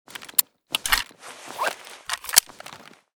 cz75_reload.ogg